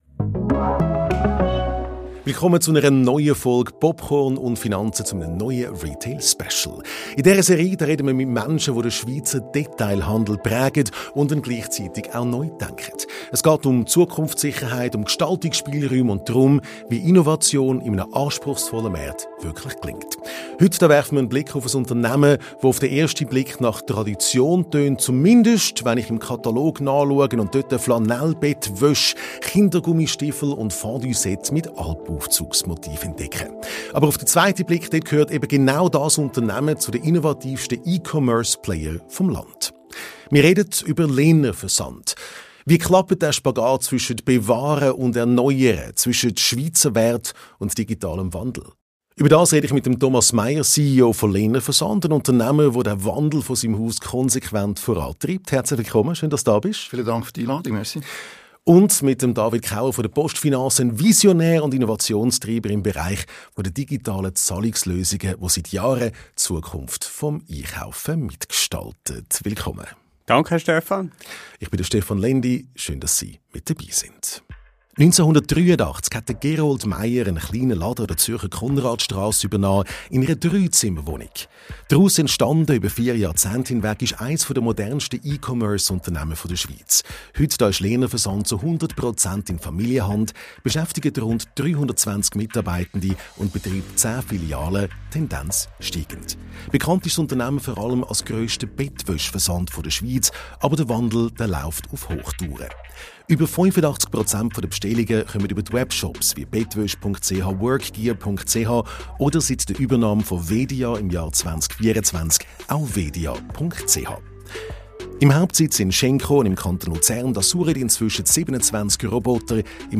Ein inspirierendes Gespräch über Zukunftssicherheit, Unternehmergeist und den Mut, Bewährtes neu zu denken.